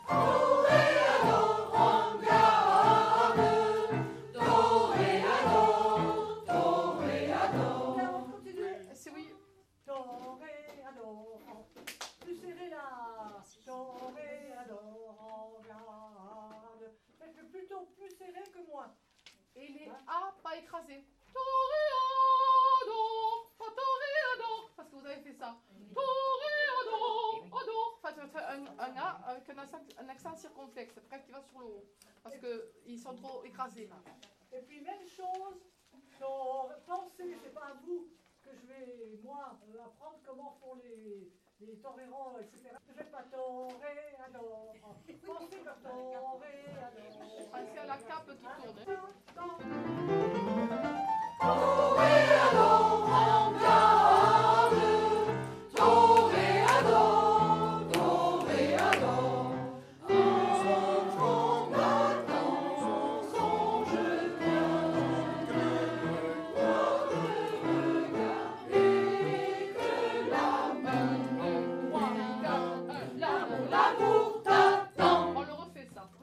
Carmen : les premières répétitions
Pour les chœurs, les organisateurs peuvent d’ores et déjà compter sur la participation de 70 chanteurs  de Vauvert, de Nîmes ou même de plus loin.
Depuis la mi-janvier, les répétitions vont bon train.